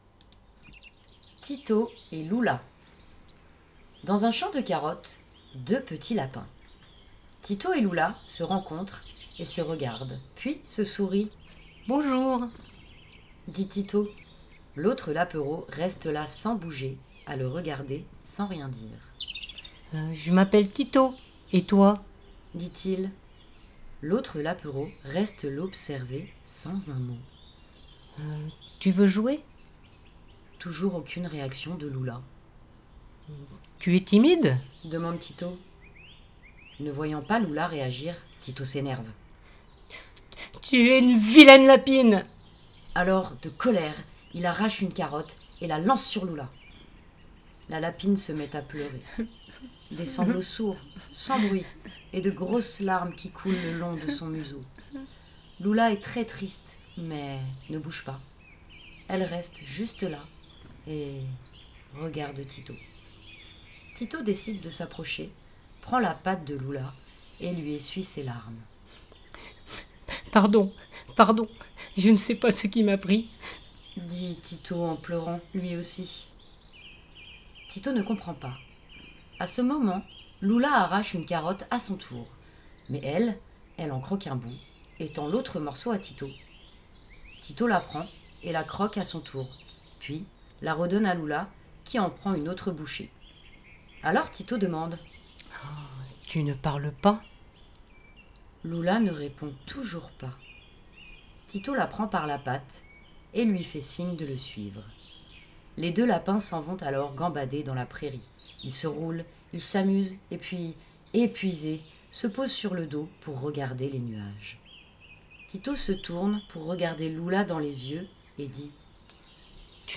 Livres audios des albums de jeunesse de chez Axélivres.